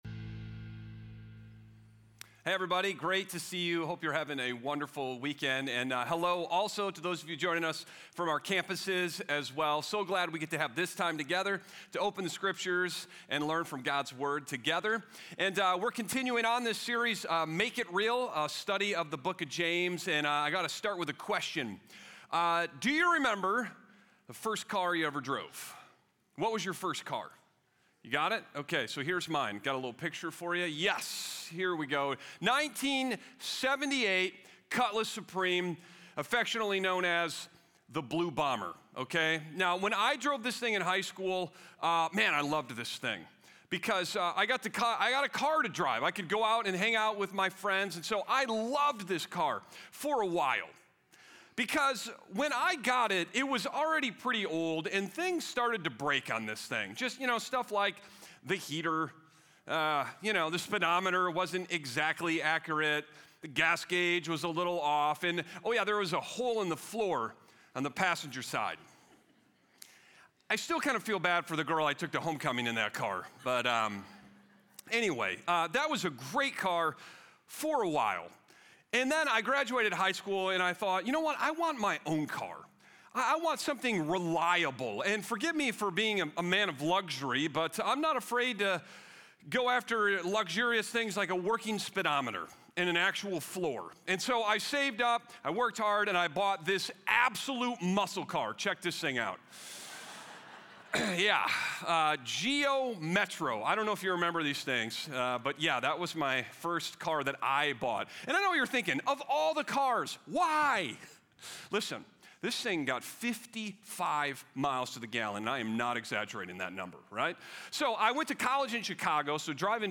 Sermon Discussion